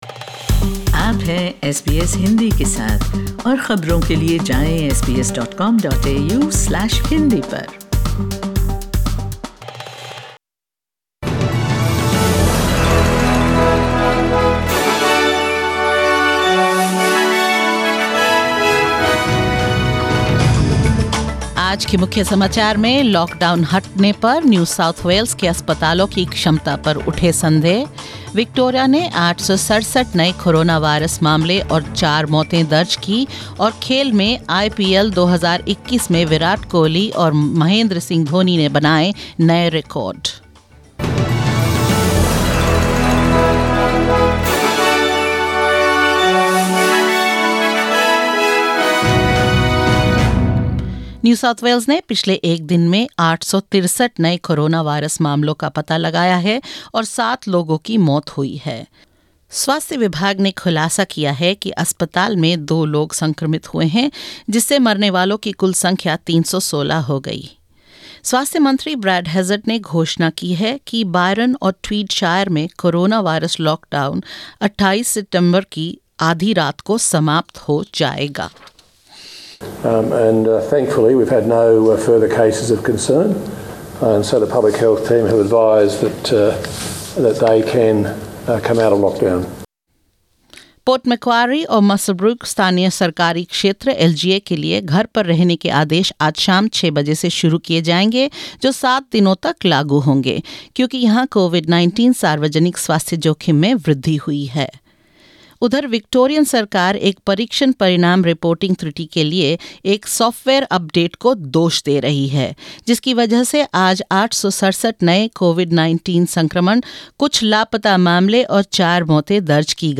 In this latest SBS Hindi News bulletin of Australia and India: Doubts raised over New South Wales hospital capacity when lockdown lifts; Victoria reports 863 newly acquired COVID-19 cases and four deaths; Virat Kohli sets record during Royal Challengers Bangalore's game against Mumbai Indians at IPL 2021 and more.